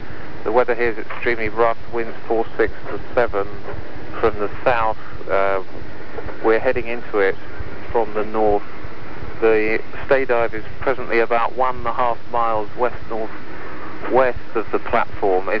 Transcripts of telephone call.
From a Greenpeace Protestor: